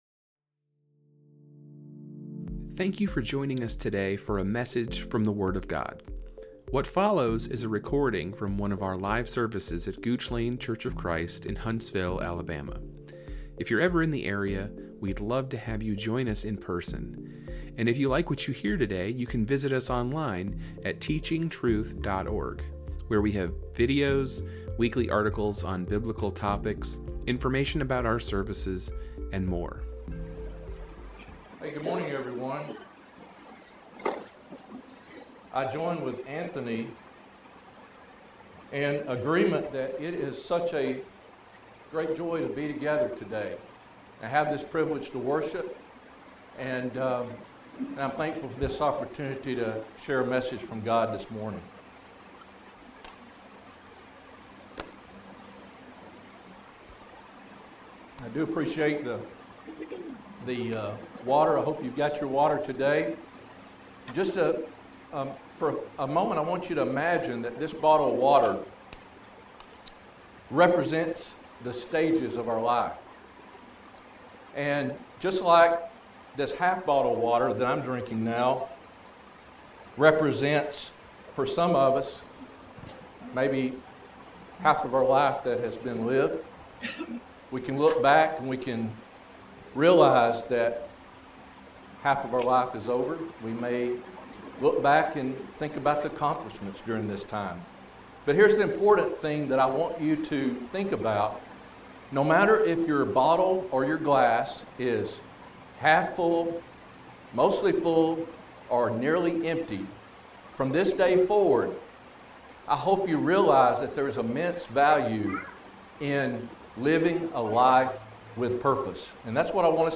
A sermon given on June 22, 2025.